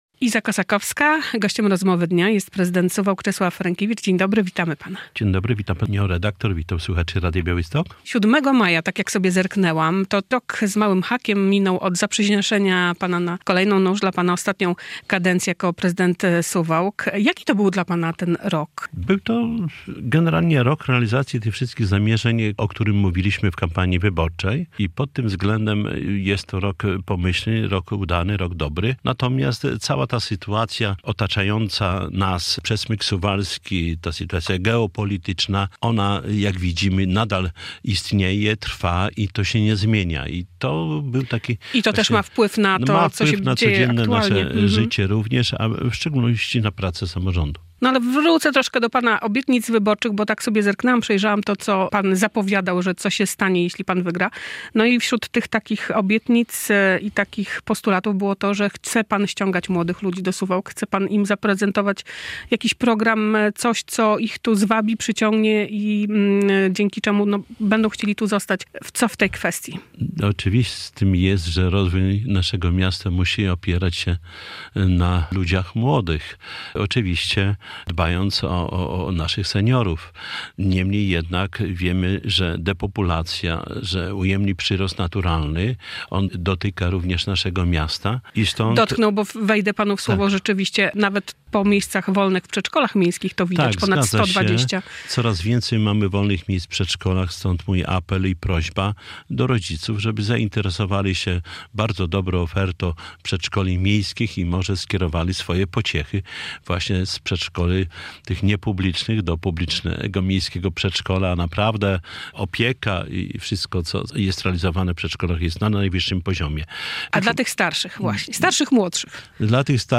prezydent Suwałk